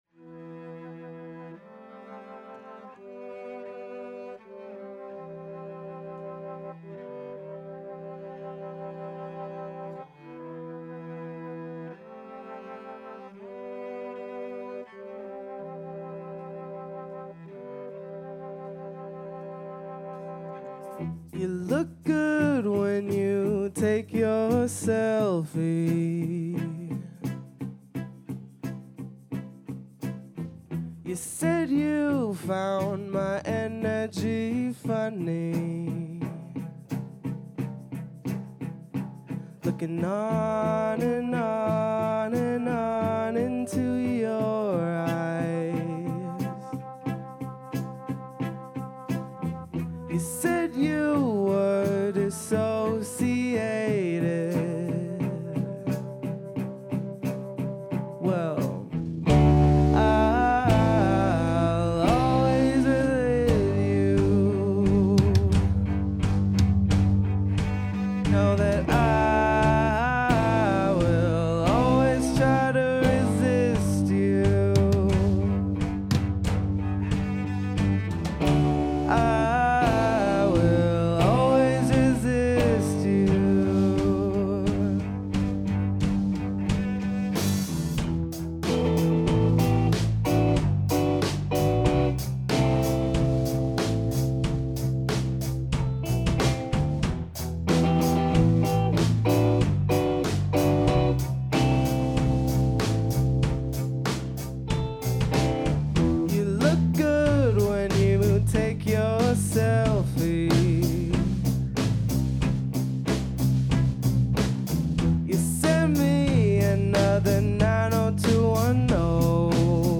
performed live
cello